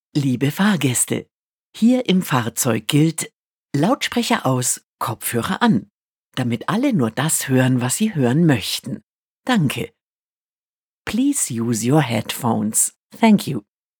Rücksichtnahme in Bus und Bahn: Neue Ansage „Lautsprecher aus – Kopfhörer an“ bei der MVG
Die Ansage ist in den Bordrechnern der Fahrzeuge hinterlegt und kann von den Fahrerinnen und Fahrern in U-Bahn, Bus und Tram bei Bedarf aktiviert werden.
mvg-ansage-handy-leise.wav